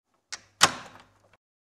دانلود آهنگ قفل زدن در از افکت صوتی اشیاء
جلوه های صوتی
دانلود صدای قفل زدن در از ساعد نیوز با لینک مستقیم و کیفیت بالا